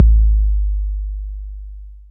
808-Kicks21.wav